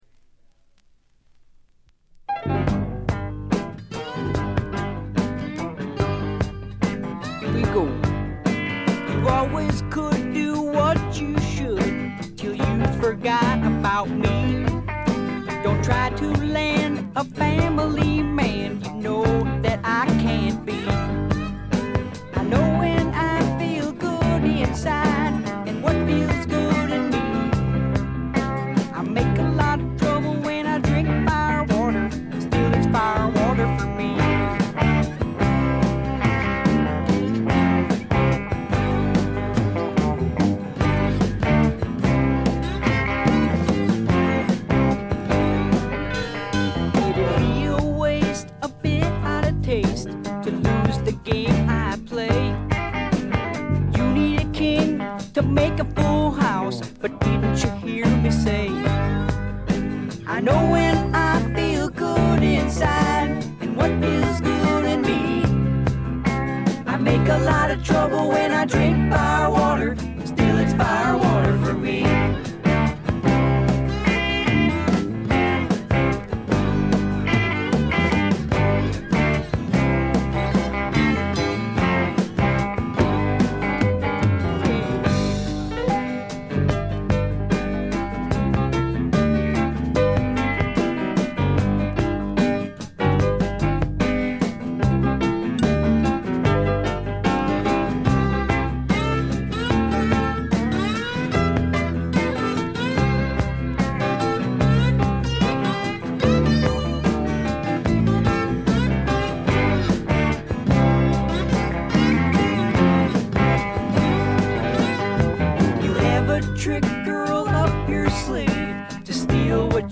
軽微なチリプチ少し。散発的なプツ音2回ほど。
ずばりスワンプ名作！
試聴曲は現品からの取り込み音源です。